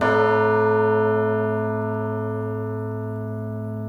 churchbell.wav